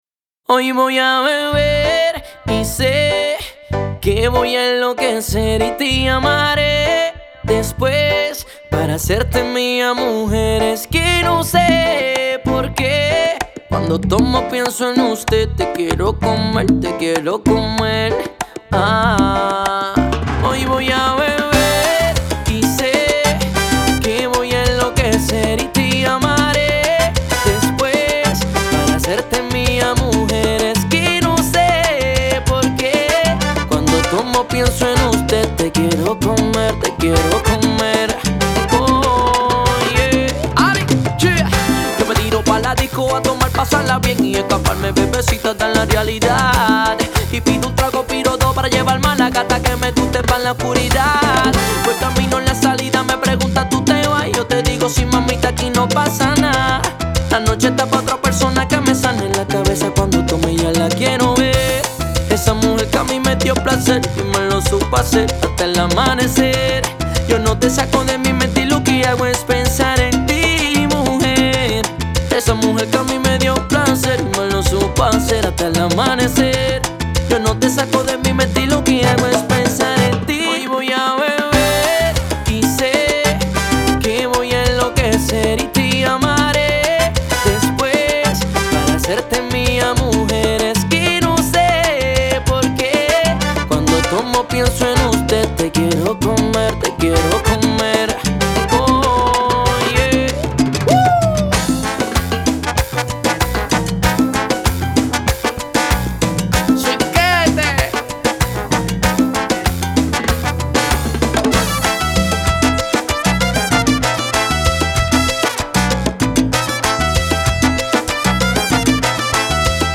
Genre: Salsa.